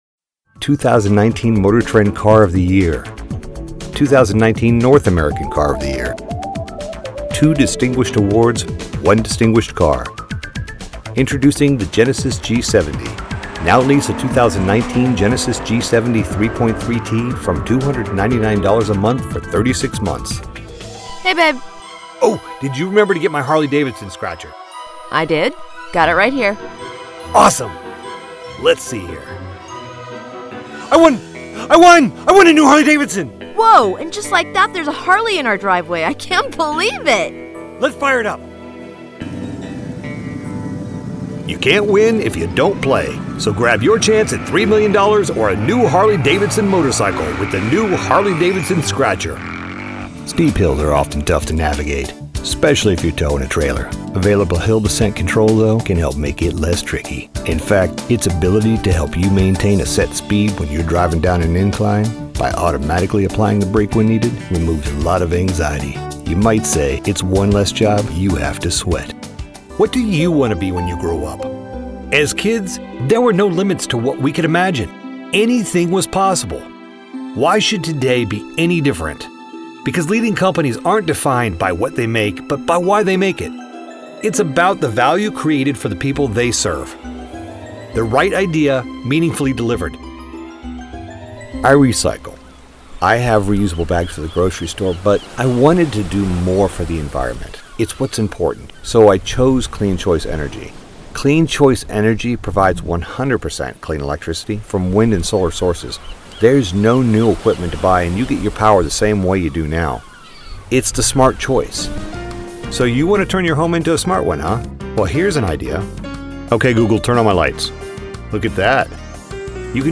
Voice Over Demos